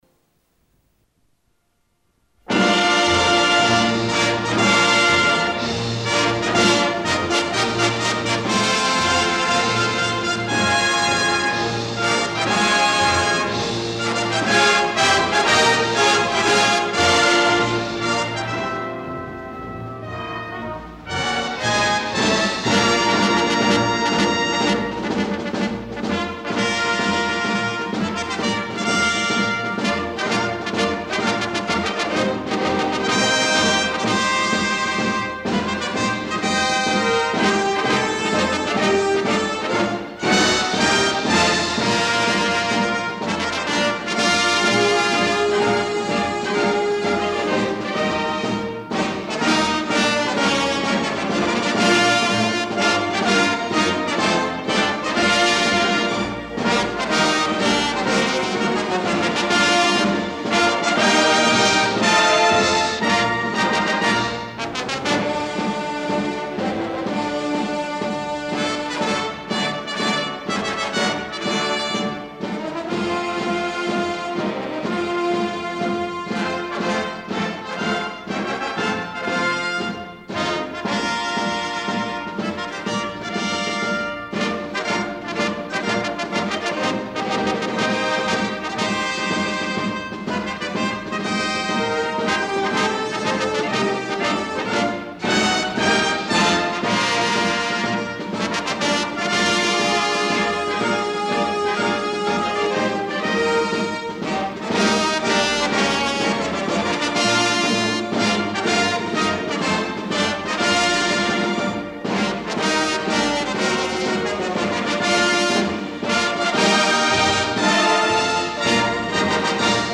Неизвестный марш